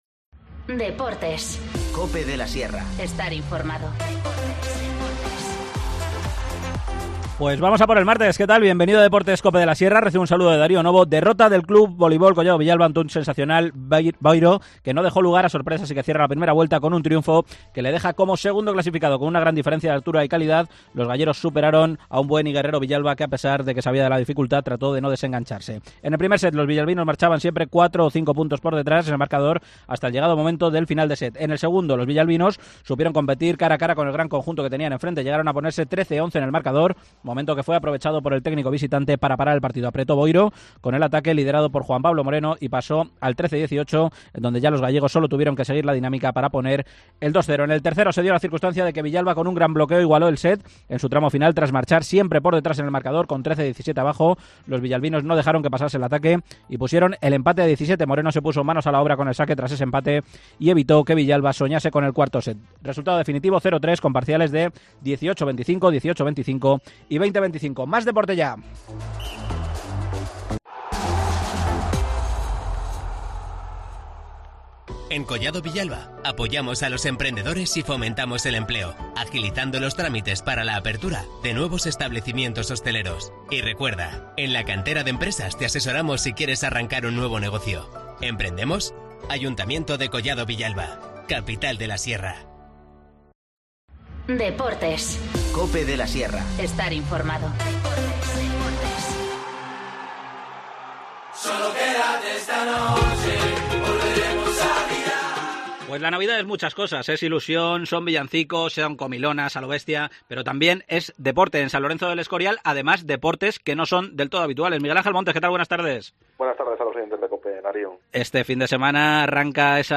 Esgrima y rugby llegan este fin de semana a San Lorenzo de El Escorial dentro de la programación navideña. Nos lo cuenta el concejal responsable de Deportes, Miguel Ángel Montes.